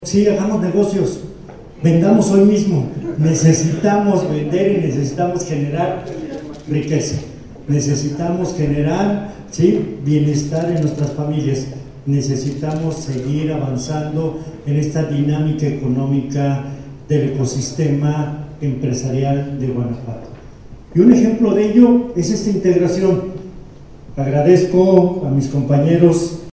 Froylán Salas Navarro, subsecretario de Desarrollo para la MipyMe